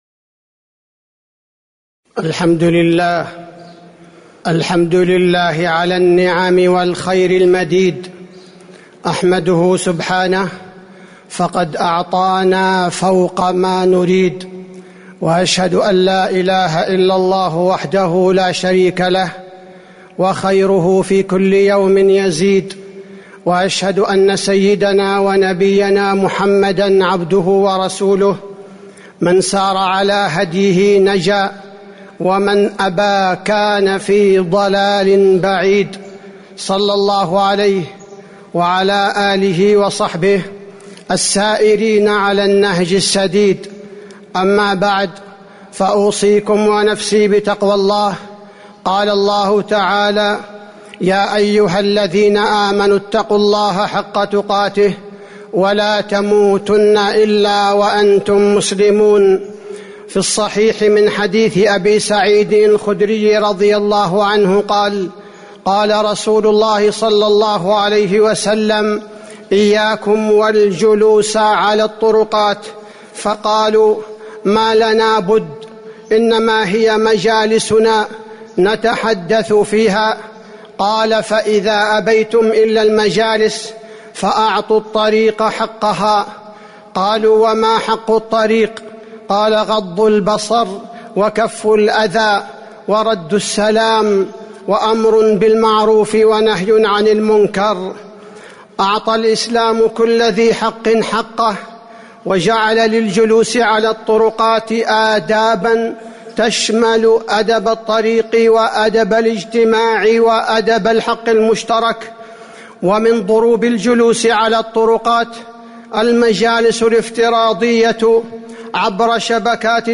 تاريخ النشر ٣ ربيع الثاني ١٤٤٤ هـ المكان: المسجد النبوي الشيخ: فضيلة الشيخ عبدالباري الثبيتي فضيلة الشيخ عبدالباري الثبيتي آداب المجالس الافتراضية